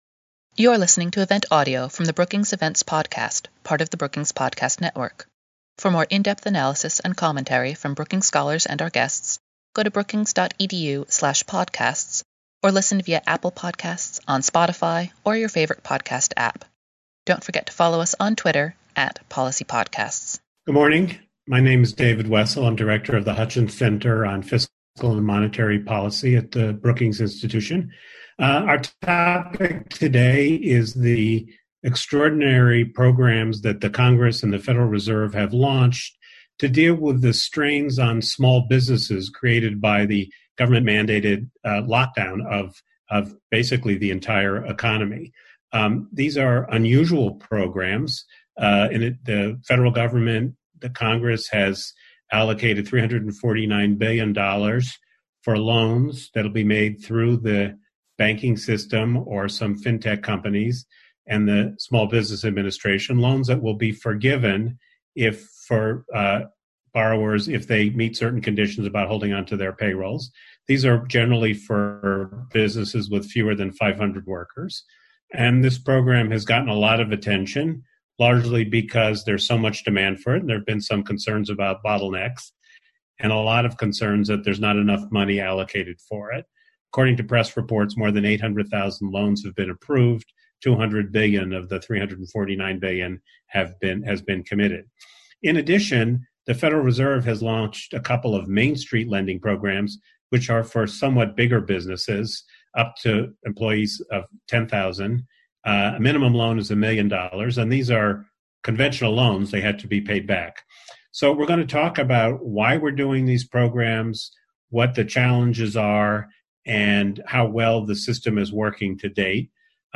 Webinar: Government lending to small businesses during COVID-19—Why? How? And will it work? | Brookings